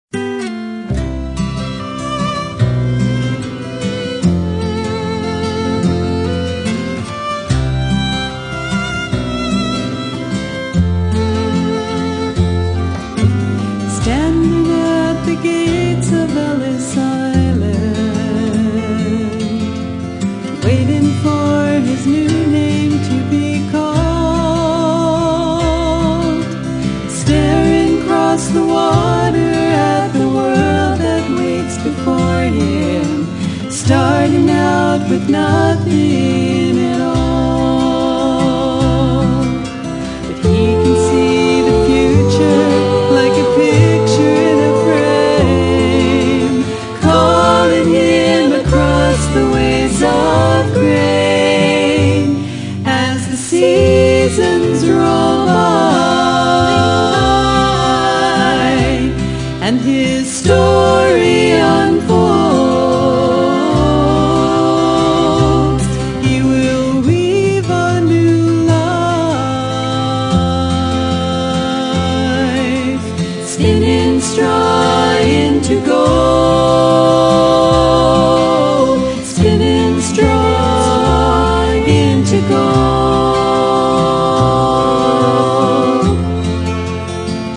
acoustic bass
Appalachian dulcimer